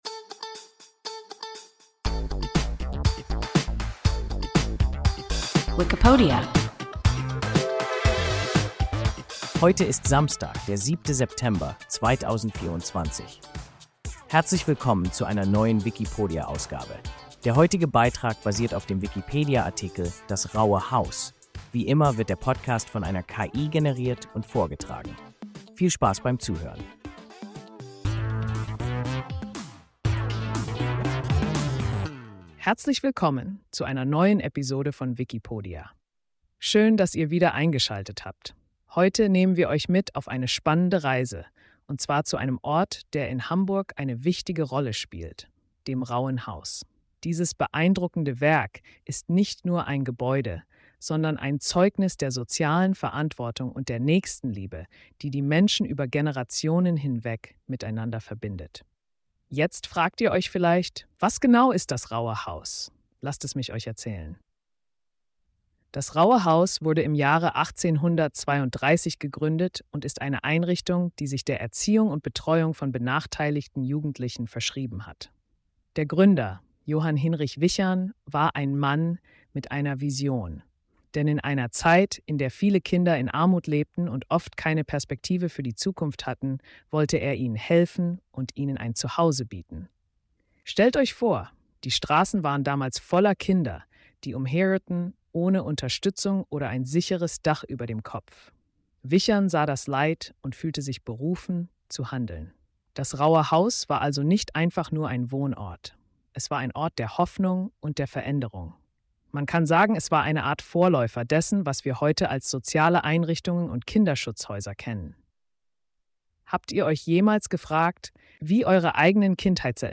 Das Rauhe Haus – WIKIPODIA – ein KI Podcast